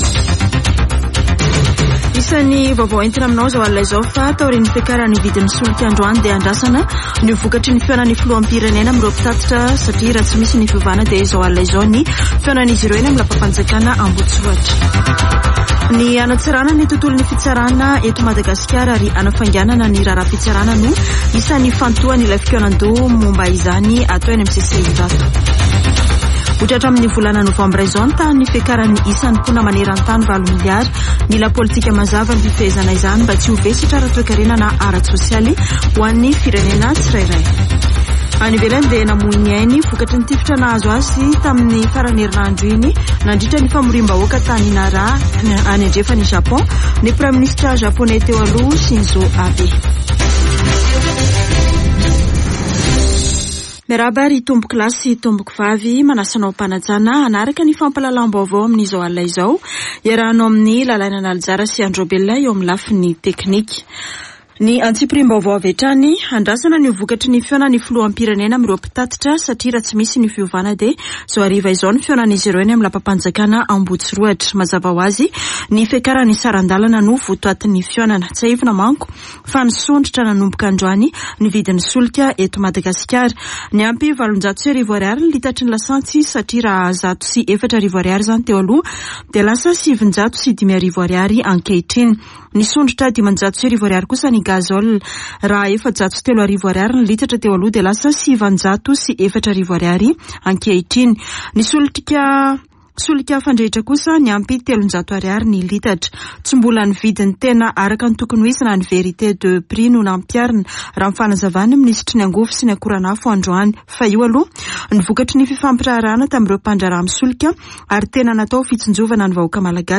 [Vaovao hariva] Alatsinainy 11 jolay 2022